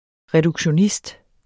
Udtale [ ʁεdugɕoˈnisd ]